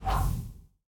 assets / minecraft / sounds / mob / breeze / land2.ogg
land2.ogg